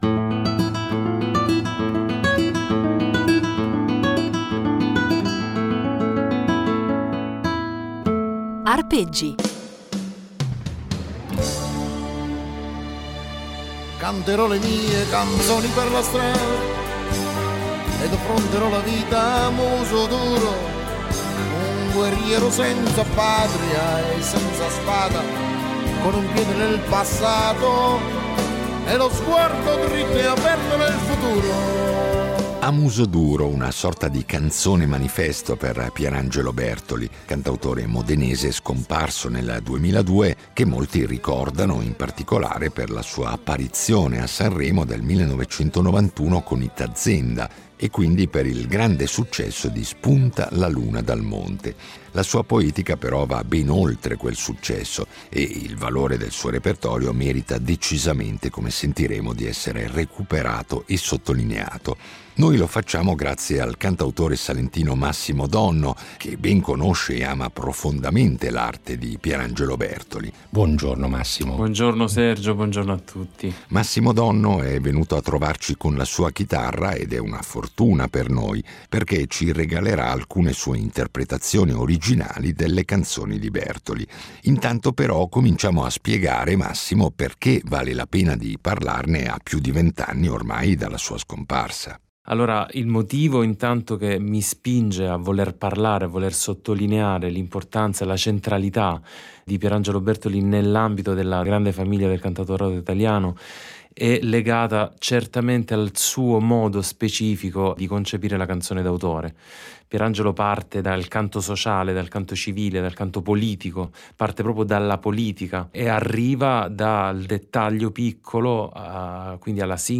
chitarra
violoncellista